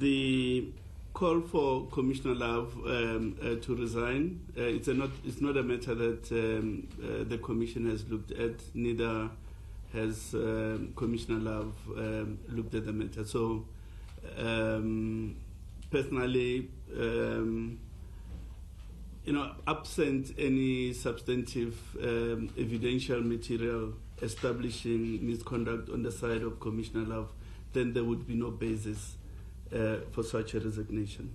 IEC’s CEO, Sy Mamabolo, says clarity is needed for future elections.